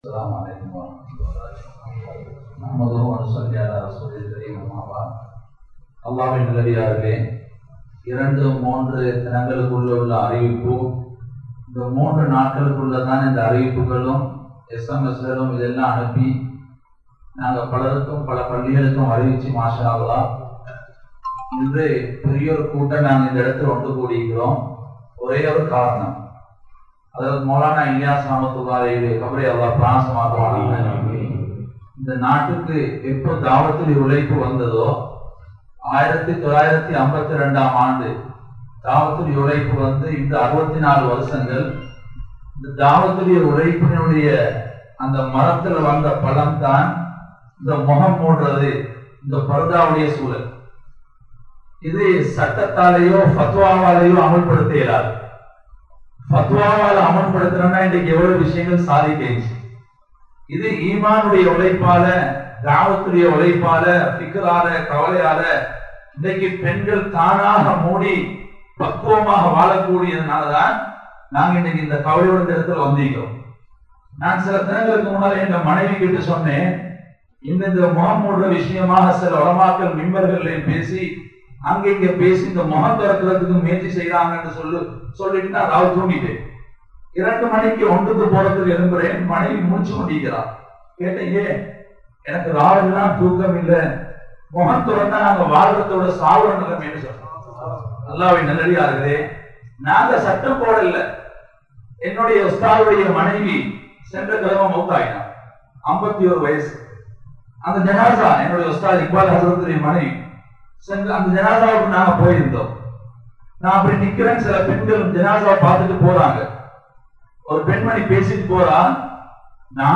Bayans
Elamalpotha, Majmaulkareeb Jumuah Masjith